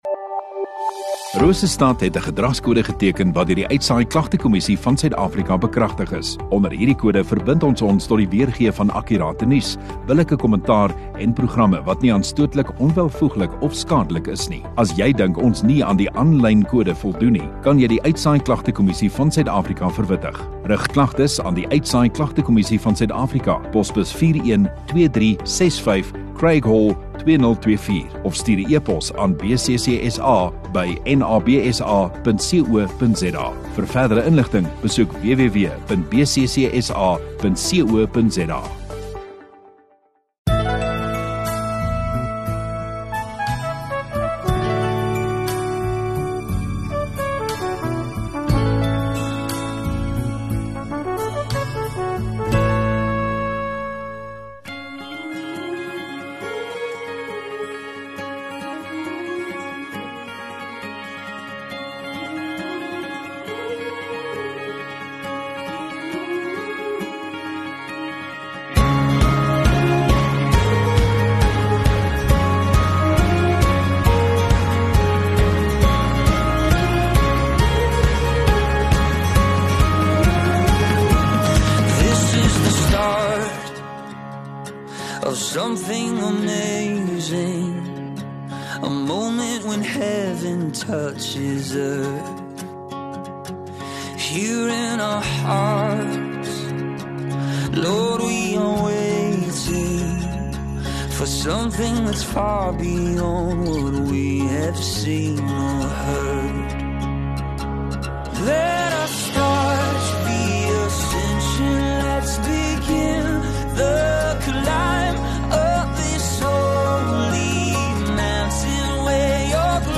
26 Oct Saterdag Oggenddiens